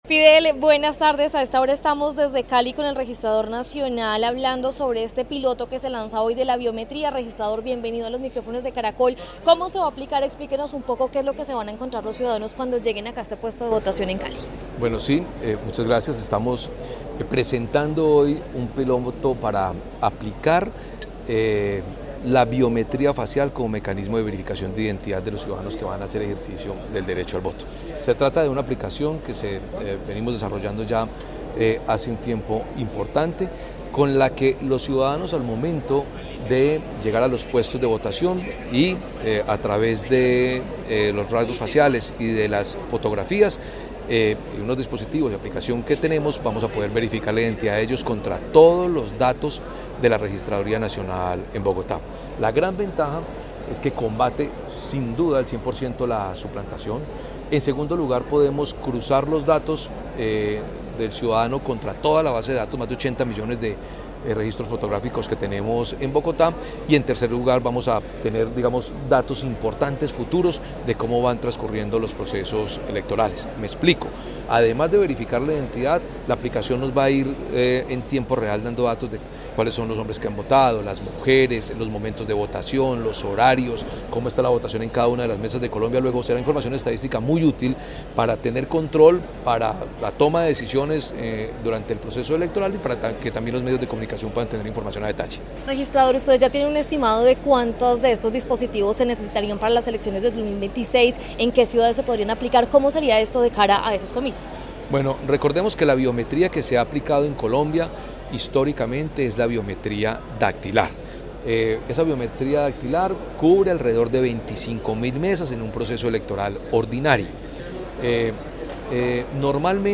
En diálogo con Caracol Radio Hernán Penagos, Registrador Nacional, explicó que esta nueva herramienta tecnológica busca fortalecer la transparencia y seguridad de los procesos electorales en el país.